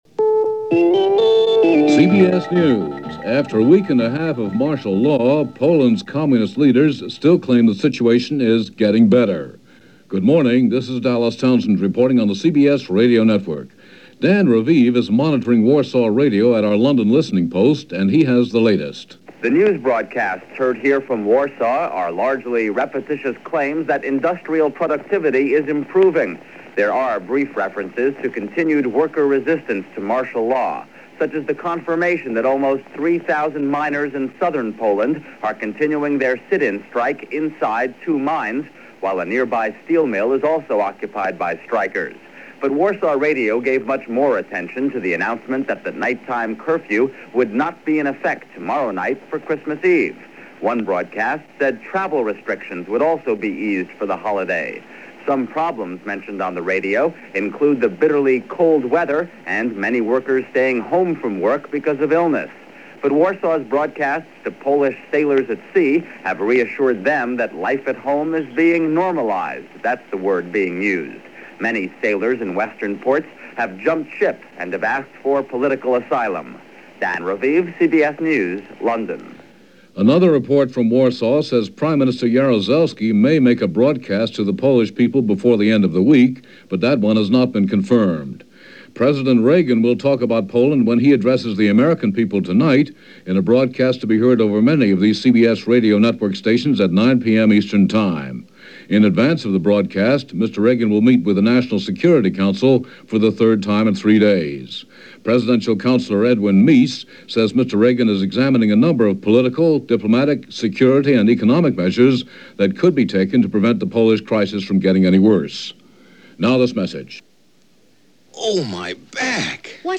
December 23, 1981 – CBS Radio News On The Hour + Spectrum Commentaries